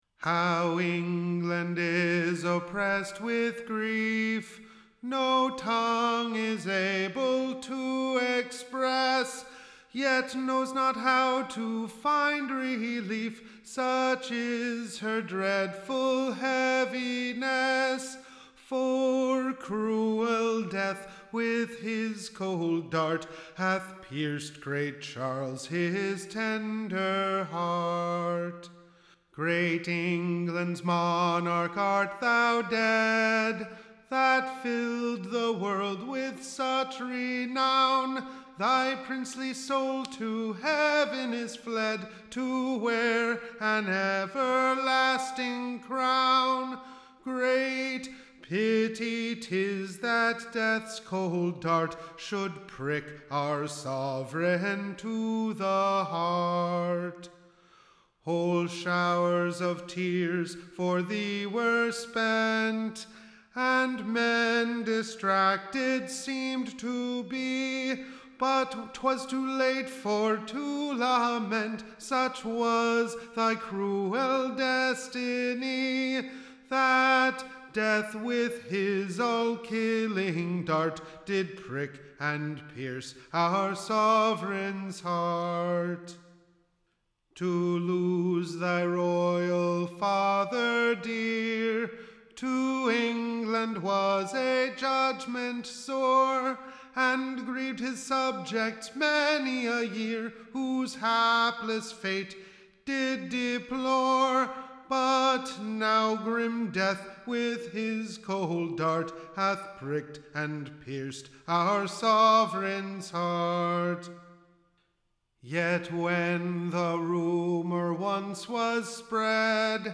Tune Imprint To the Tune of, Troy Town.